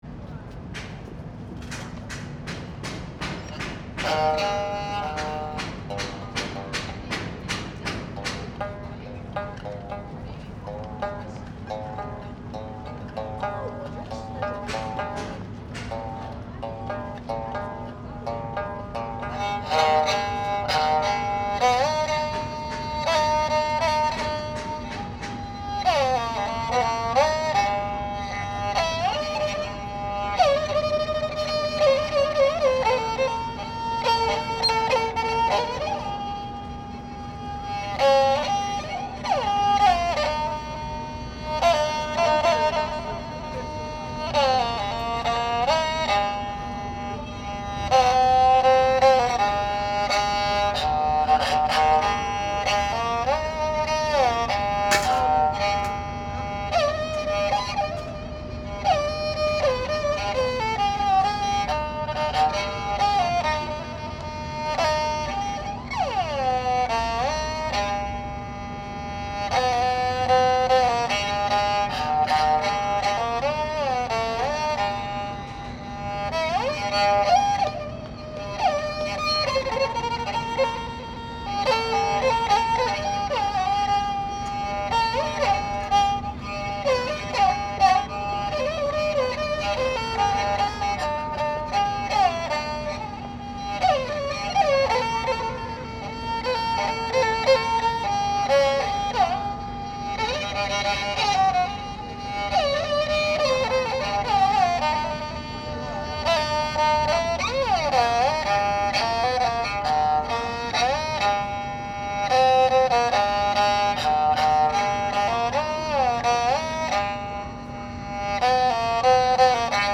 Sound recorded in Beijing, Shanghai, Hong Kong and Macao.
- Shanghai street musician near a crowded street (3) (5:42)
shanghai_street_musician_3.mp3